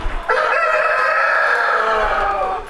白色コーニッシュ
オスの鳴き声 [WAVファイル:464.1KB] メスの鳴き声 [WAVファイル:982.1KB]